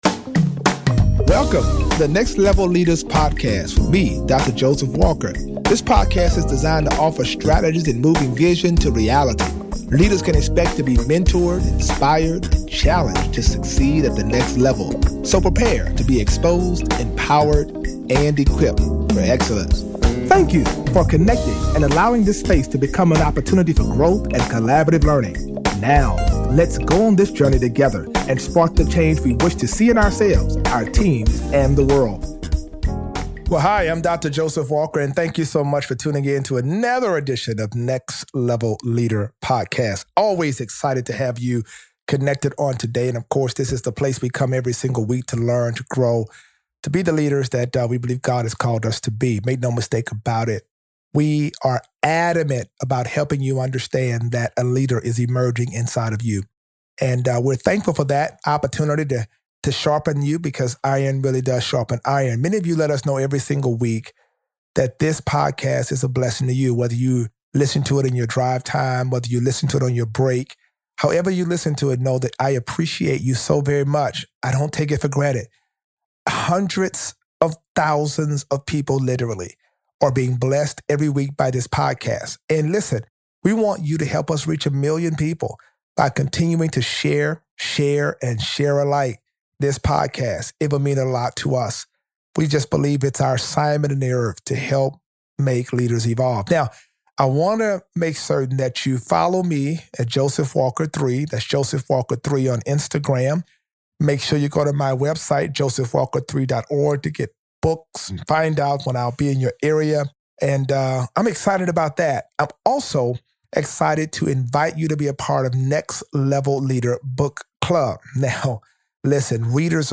Each episode addresses the intersect between Christianity and the marketplace through conversations with successful leaders.